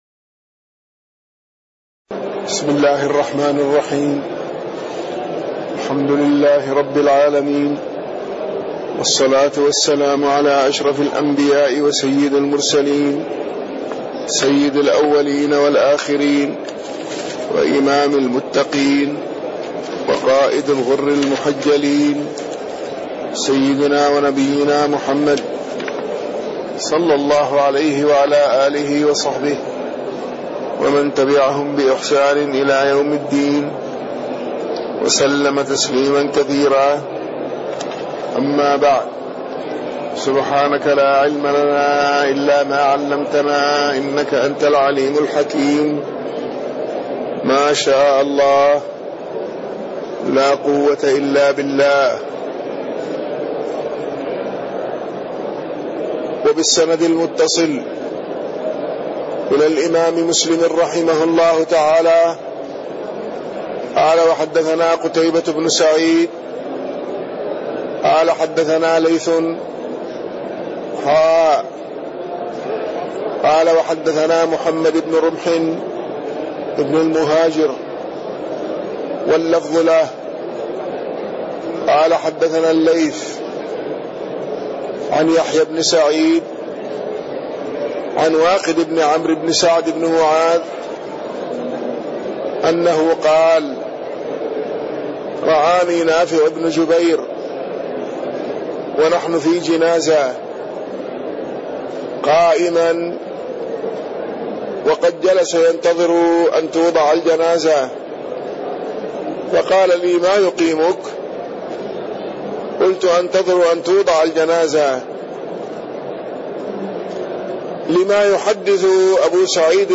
تاريخ النشر ٤ ربيع الثاني ١٤٣٢ هـ المكان: المسجد النبوي الشيخ